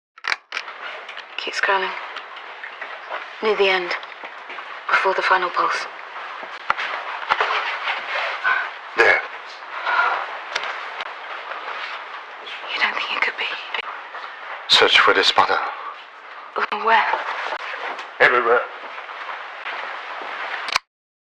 I can't quite make out what he's saying in the audio file though.